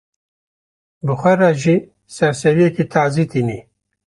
Pronunciado como (IPA)
/tɑːˈziː/